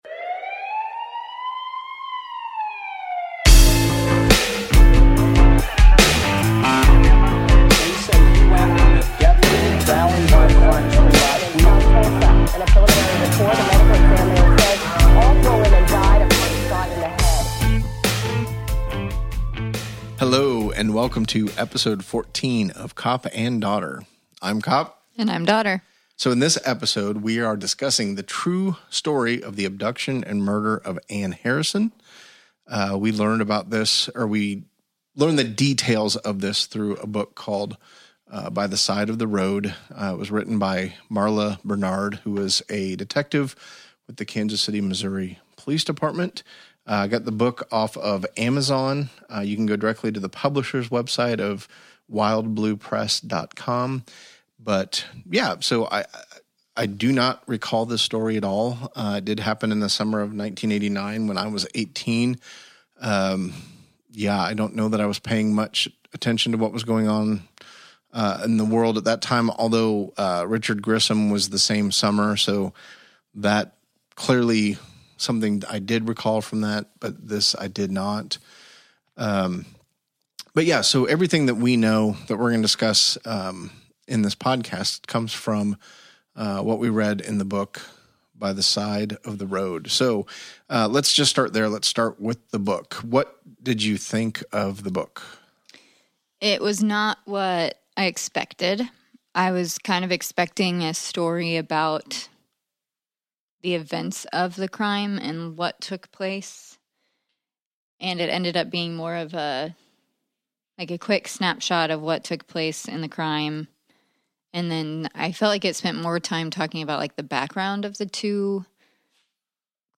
Join us as this dad and daughter duo chat about all things true crime.